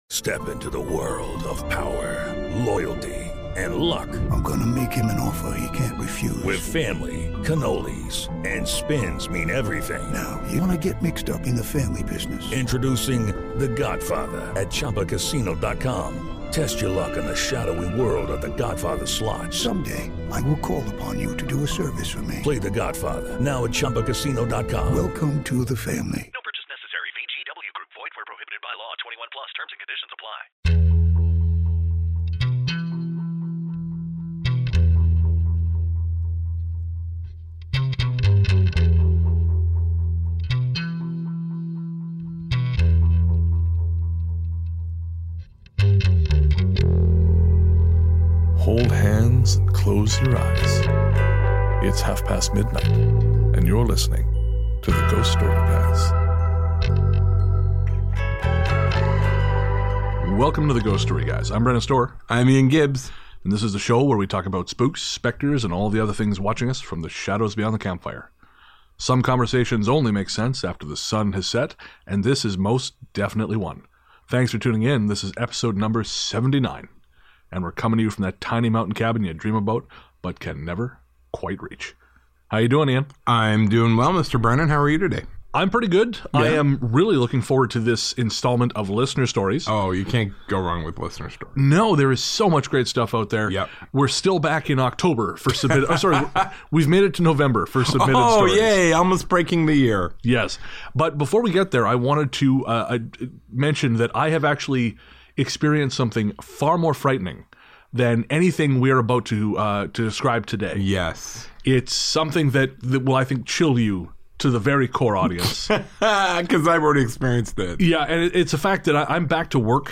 Musical guest on this episode: Galactic Cinema If you have a story you want to share, now you can text it to us or leave us a voicemail!